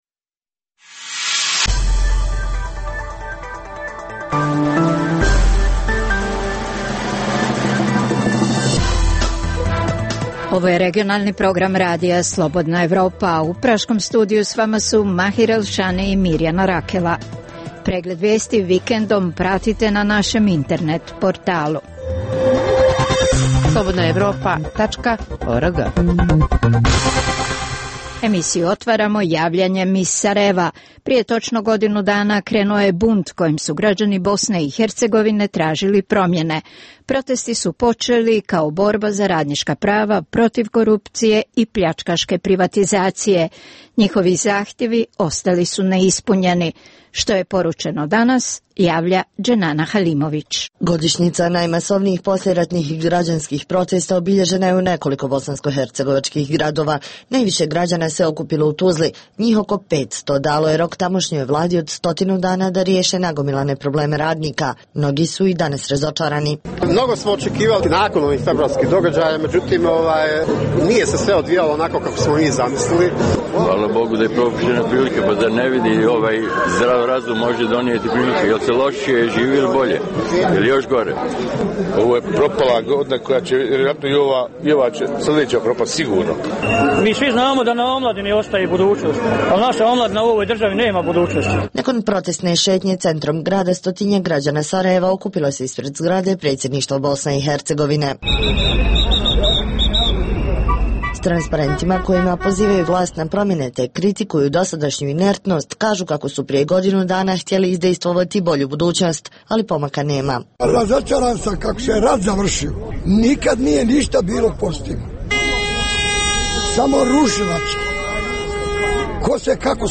- Intervju s Ivanom Krastevom, direktorom Centra za strateške studije u Sofiji. - Reportaža s ilegalnim migrantima s Kosova.